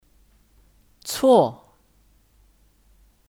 错 (Cuò 错)